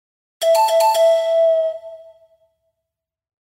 1. Эта мелодичная вставка сразу раскрывает суть